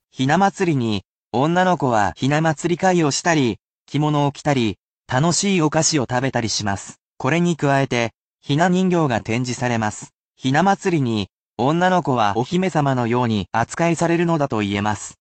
Each sentence is then read to you as many times as you wish, but it will be at full speed, so it is more useful to be used to pick the word out of the sentence rather than repeating.